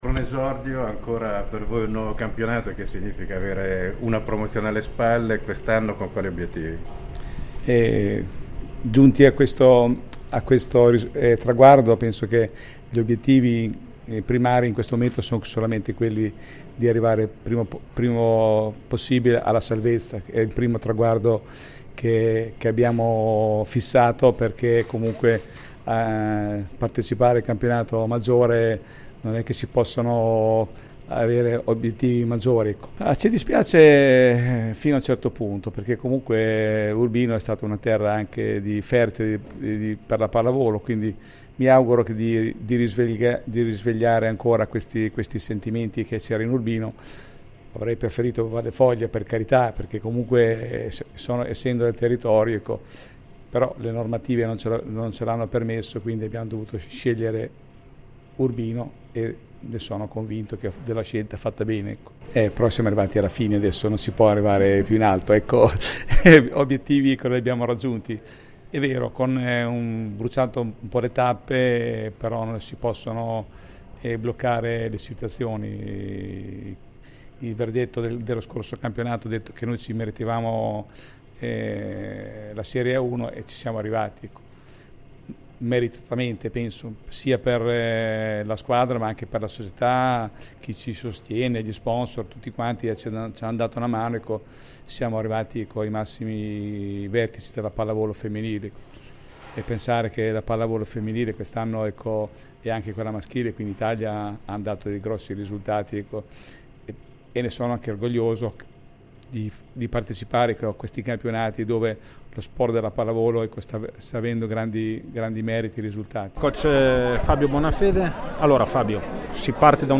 Bagno di folla per la Megabox Ondulati Del Savio Vallefoglia, presentata a stampa, autorità e sponsor all’Urbino Resort.